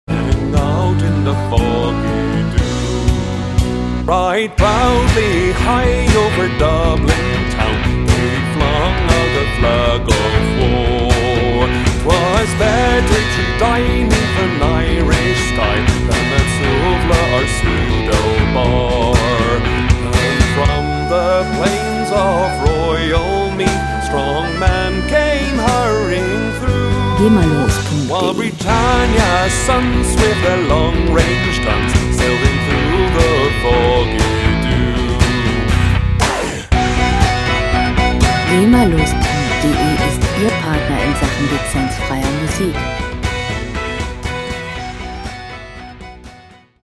Musikstil: Irsh Folk
Tempo: 119,5 bpm
Tonart: H-Moll
Charakter: kämpferisch, intensiv